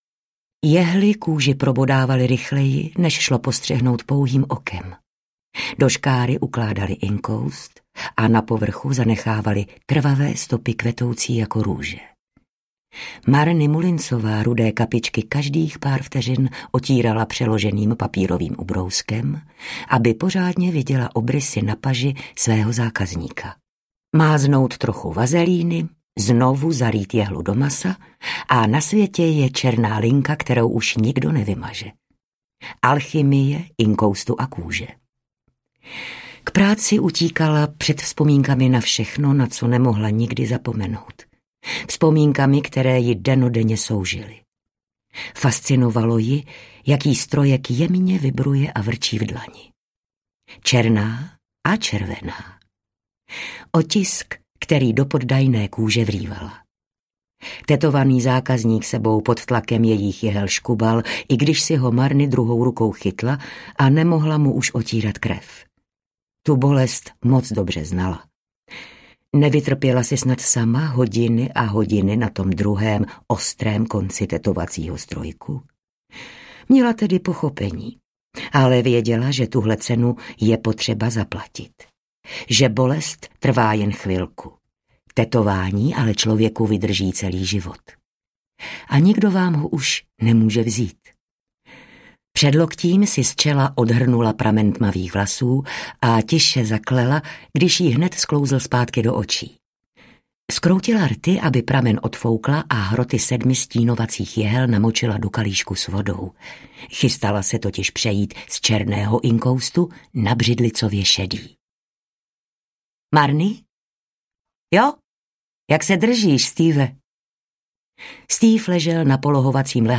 Tatérka audiokniha
Ukázka z knihy